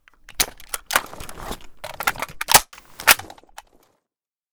Divergent/l85_reload.ogg at main
l85_reload.ogg